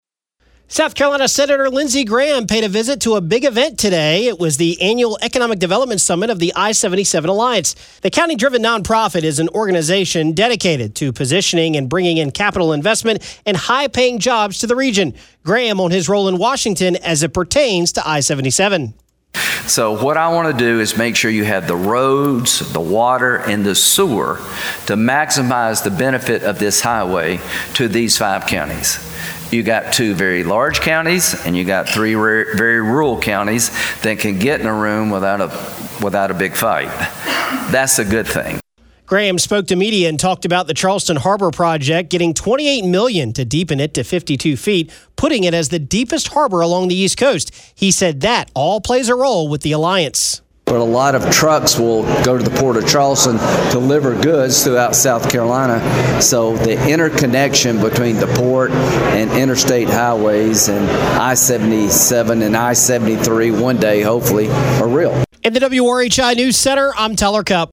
AUDIO: Senator Lindsey Graham speaks at the annual economic development summit of the I-77 Alliance